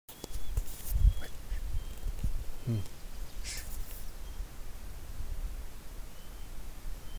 Dessverre vet jeg at det kom til å bli vanskelig å identifisere bare ved denne beskrivelsen, så jeg fikk tatt opp lyden den lagde. Har skrudd opp lyden i redigerings program, men den er fortsatt ganske lav, men man kan høre den om man skrur opp volumet litt.